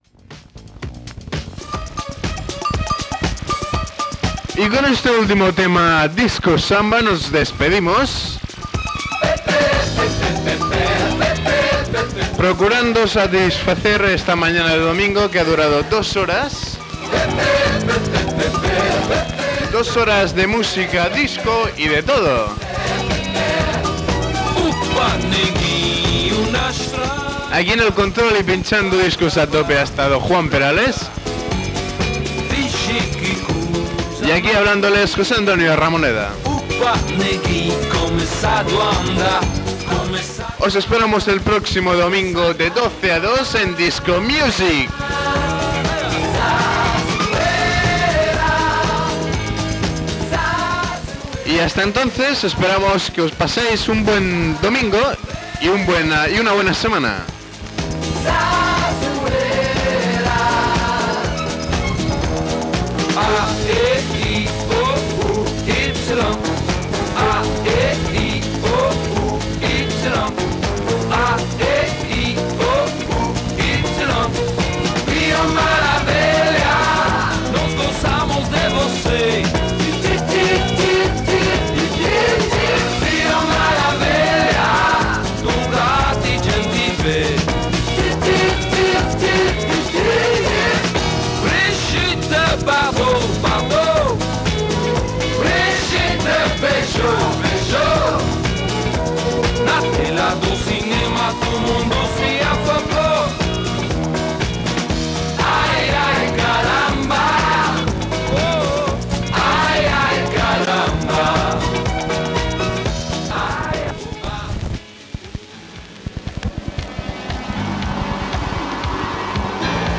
Final de "Disco Music" i inici de "Disco Dedicado" amb les dues primeres trucades telefòniques.
Musical